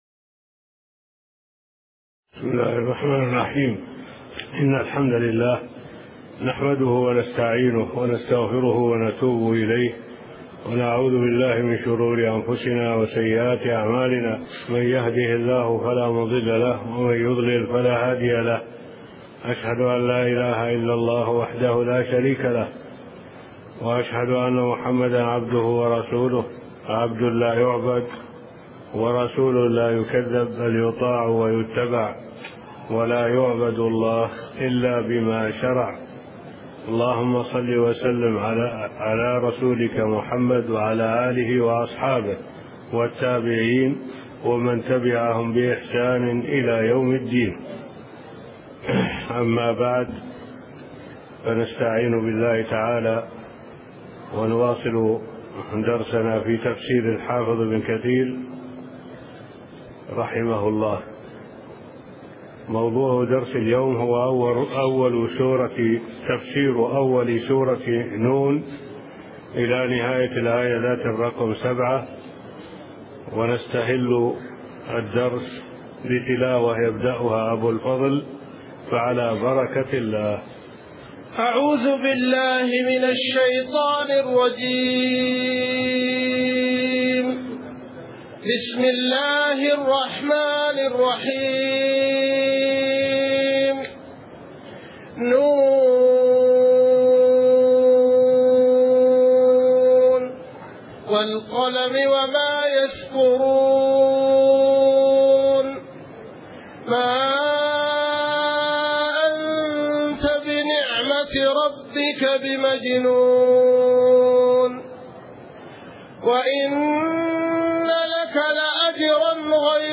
تفسير جزء تبارك من تفسير بن كثير لفضيلة الشيخ صالح العبود من دروس الحرم المدنى